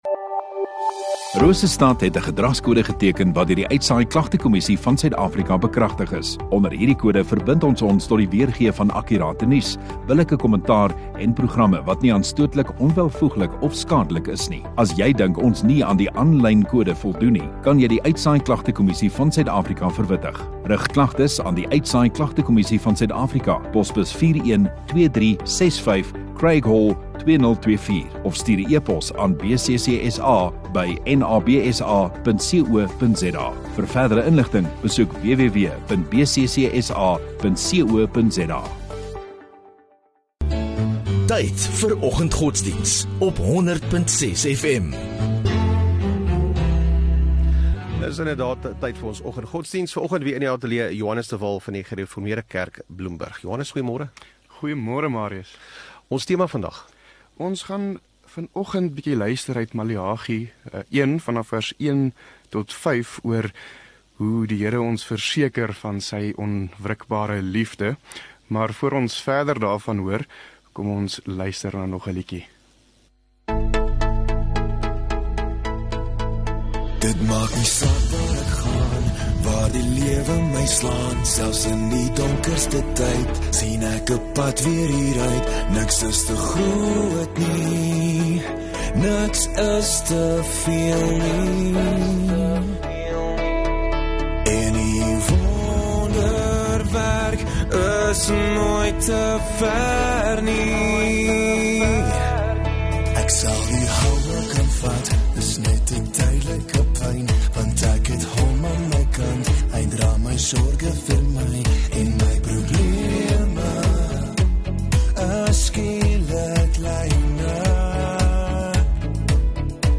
1 Aug Donderdag Oggenddiens